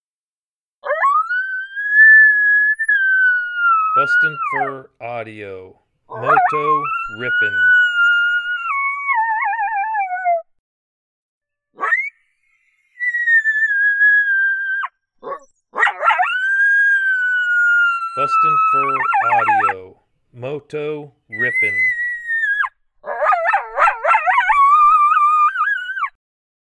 BFA’s MotoMoto doing his thing, howling with enthusiasm that for sure fires up the wild coyotes.
• Product Code: howls